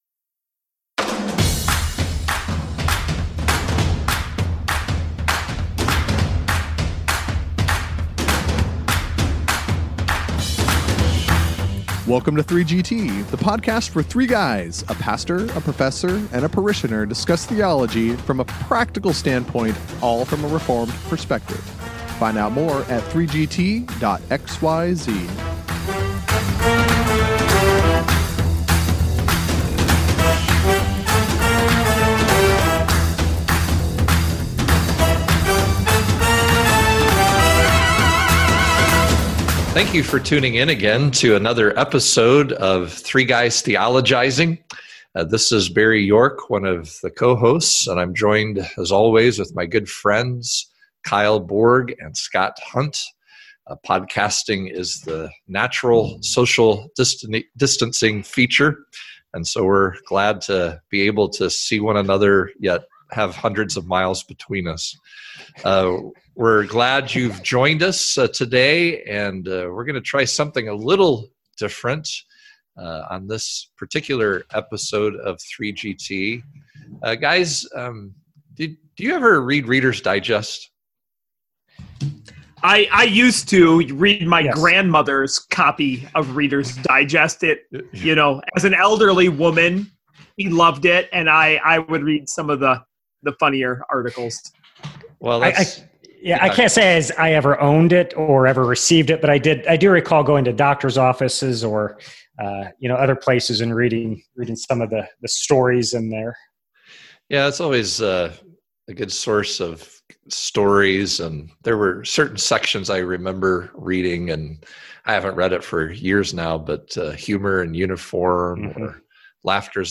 And, if nothing else, you will be cheered simply by hearing the hearty, charming laugh of our parishioner!